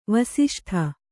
♪ vasiṣṭha